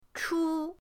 chu1.mp3